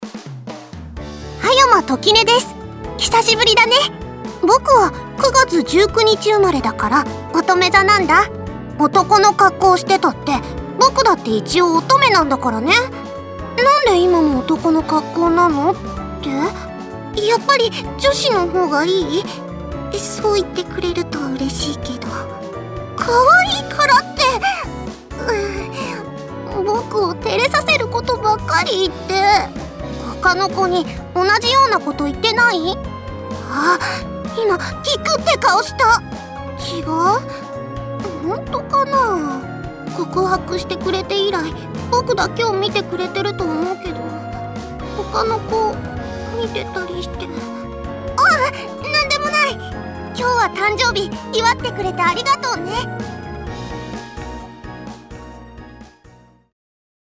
葉山秋音誕生日記念ボイスを公開しました！